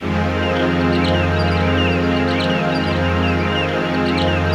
ATMOPAD30.wav